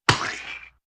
splat7.ogg